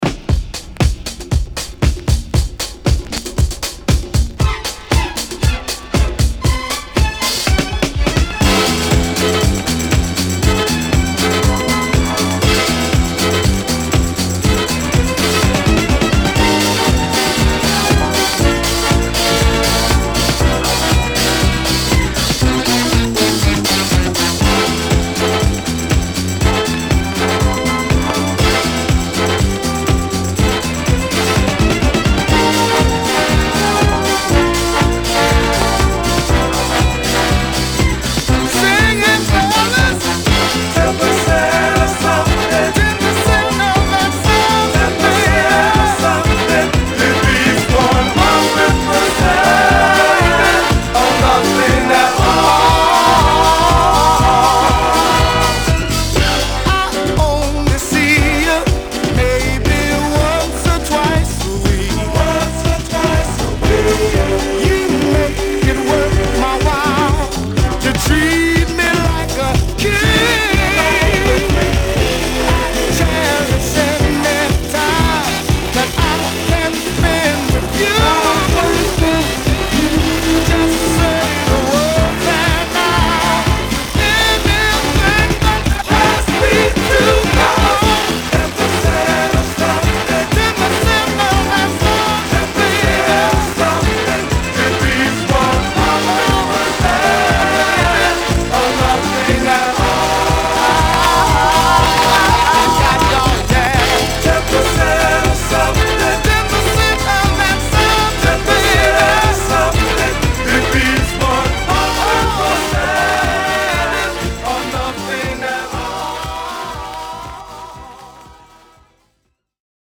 Disco~Garage Classic!!